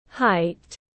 Chiều cao tiếng anh gọi là height, phiên âm tiếng anh đọc là /haɪt/.
Height /haɪt/